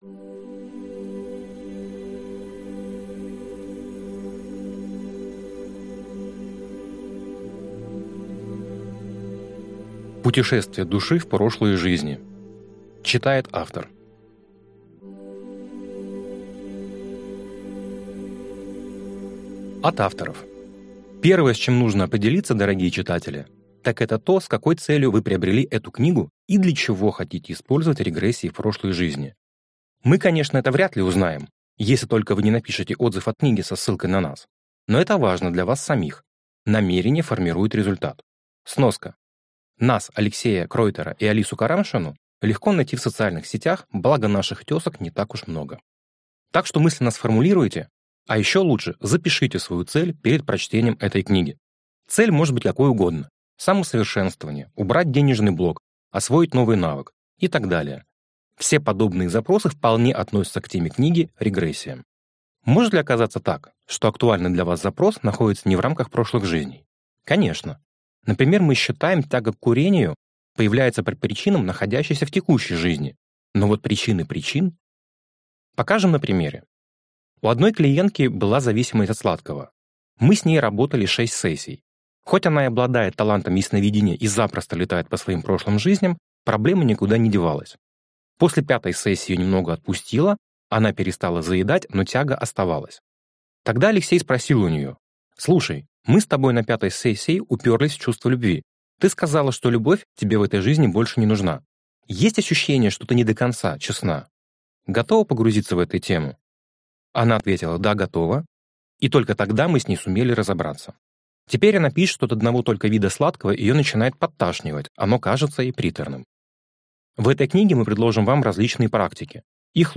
Аудиокнига Путешествие души в прошлые жизни | Библиотека аудиокниг